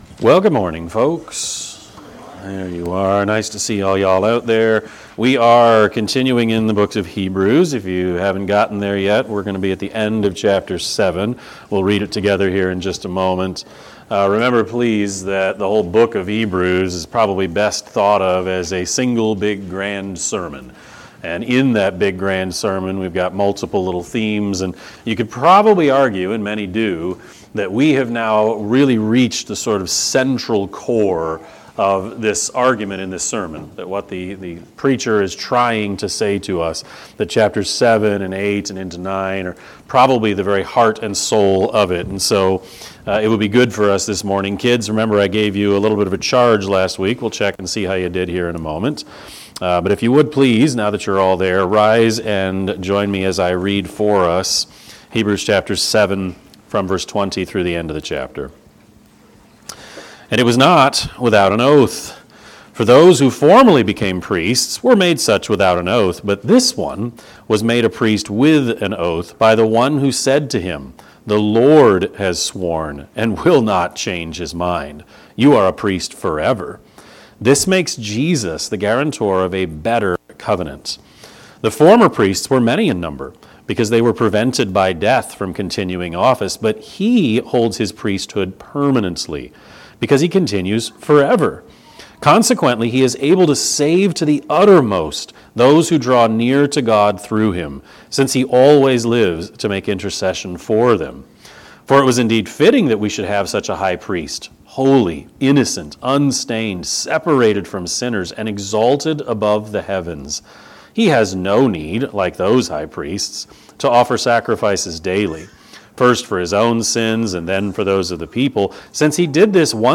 Sermon-8-31-25-Edit.mp3